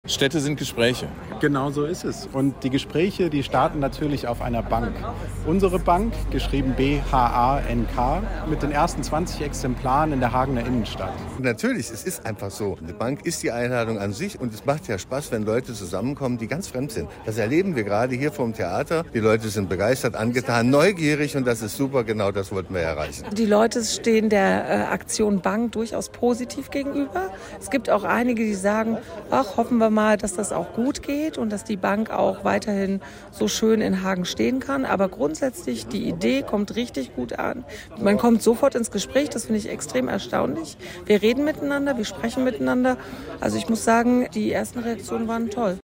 Die Organisatoren